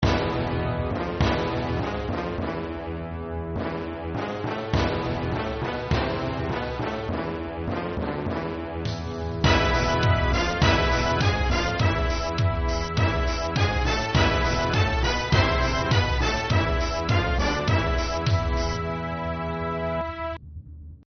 (инструментальный); темп (85); продолжительность (3:00)